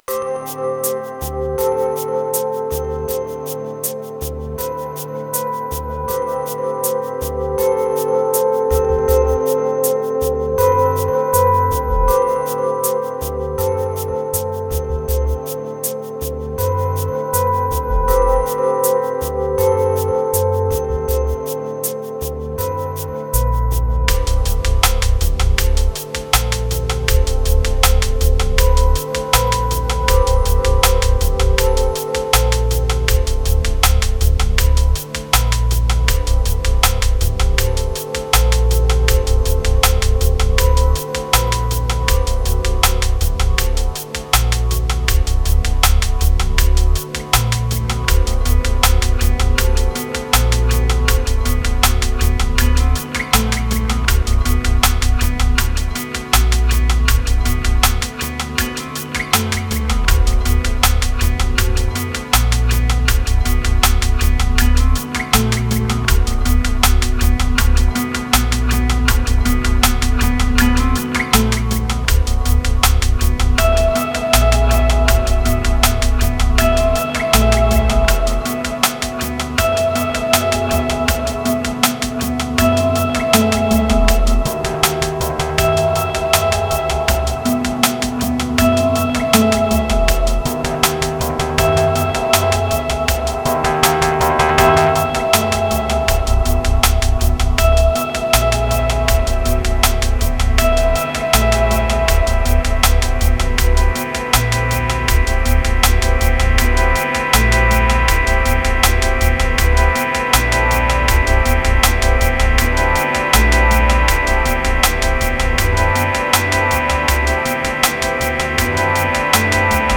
784📈 - 60%🤔 - 80BPM🔊 - 2020-11-22📅 - 560🌟
Riddim Abuse Guitars Triphop Relief Sacred Weekend